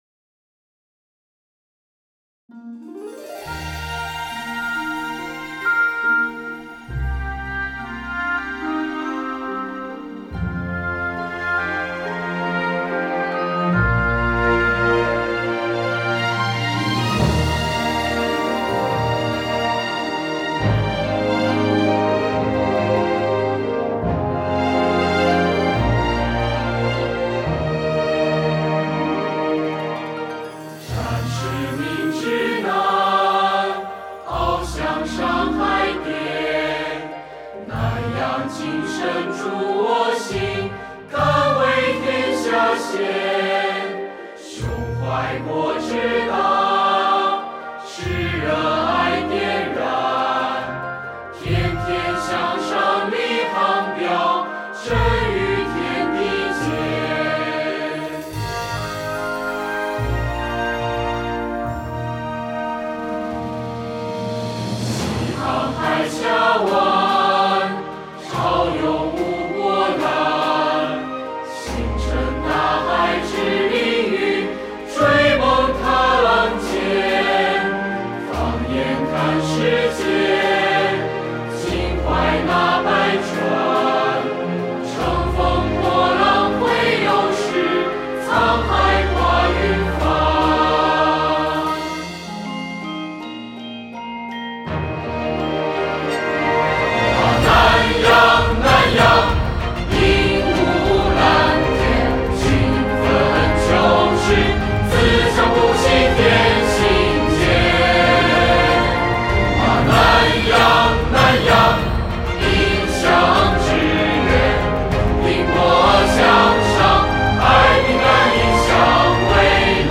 厦门南洋学院校歌（合唱版）.mp3